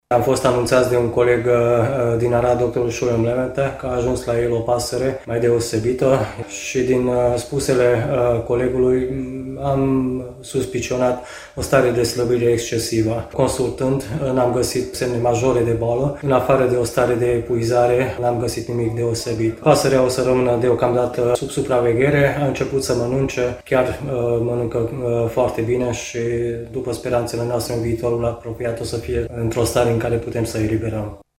Medicul veterinar